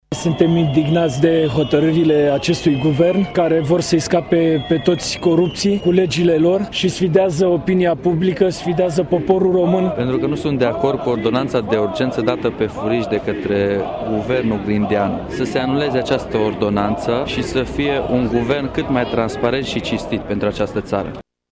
La Brașov, peste 600 de persoane s-au strâns, începând cu ora 18,00, în faţa clădirii Prefecturii Braşov. Oamenii scandează lozinci împotriva Guvernului şi a PSD, fluieră şi huiduie.